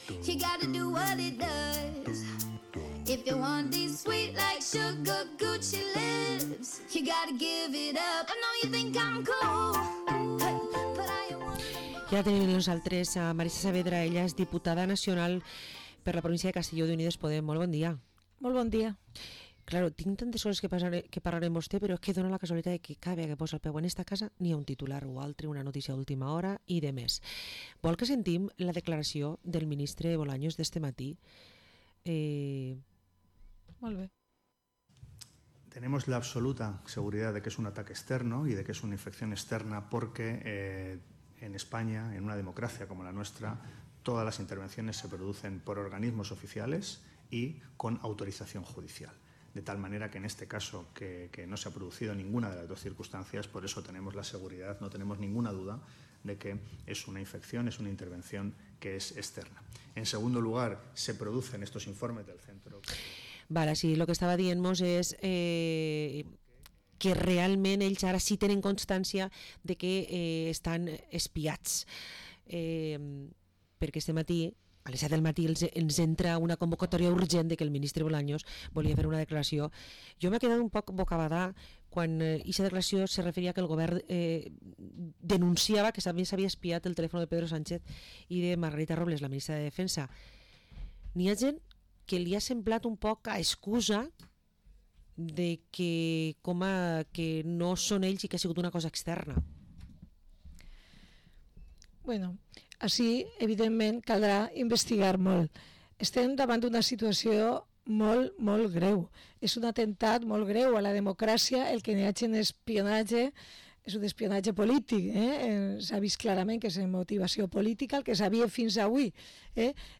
Entrevista a la diputada nacional de Unidas Podemos, Marisa Saavedra